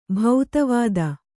♪ bhauta vāda